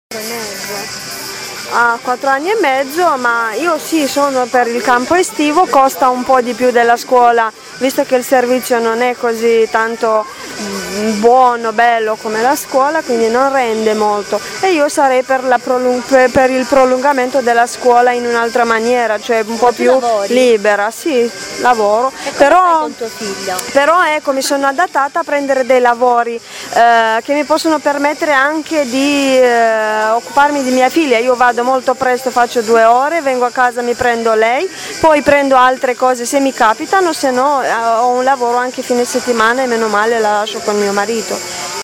Abbiamo fatto un giro nei parchi di Bologna alla ricerca di mamme con pargoli e abbiamo chiesto ad alcune di loro un’opinione sui campi estivi.